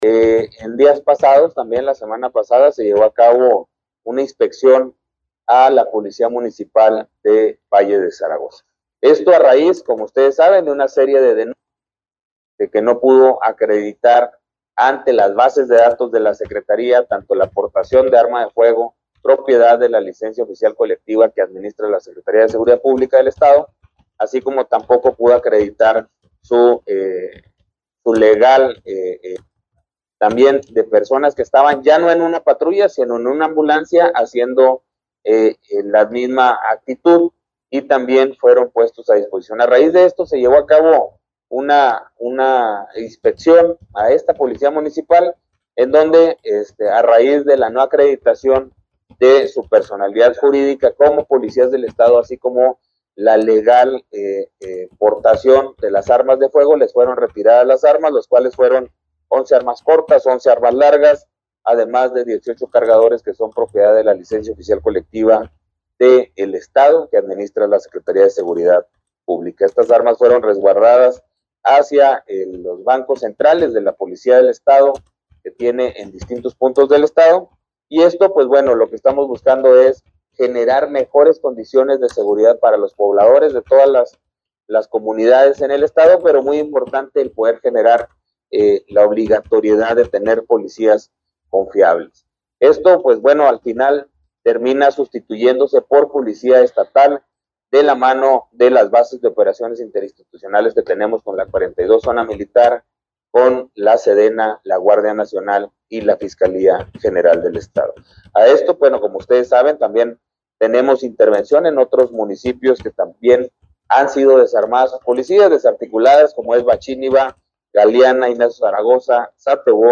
AUDIO: GILBERTO LOYA CHÁVEZ, SECRETARIO DE SEGURIDAD PÚBLICA DEL ESTADO